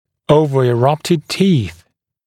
[ˌəuvəɪ’rʌptɪd tiːθ][ˌоувэи’рапид ти:с]перепрорезавшиеся зубы, выдвинутые зубы